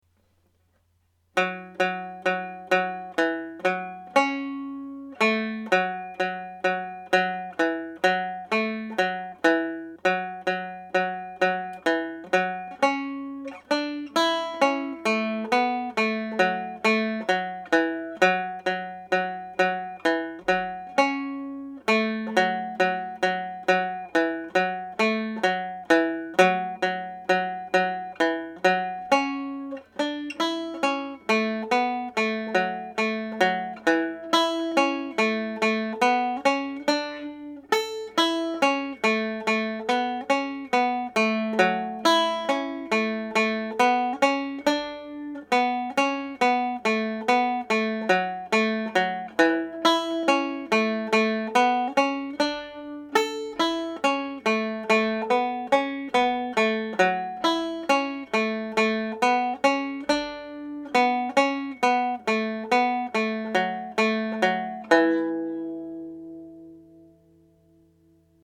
Cathal McConnell’s slip jig played slowly